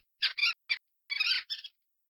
Divergent / mods / Soundscape Overhaul / gamedata / sounds / monsters / rat / attack_0.ogg
attack_0.ogg